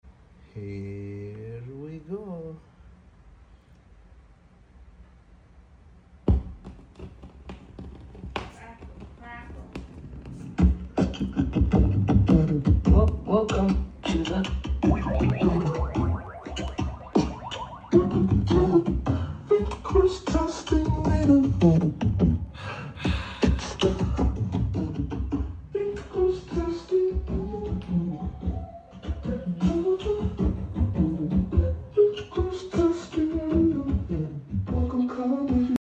AI generated music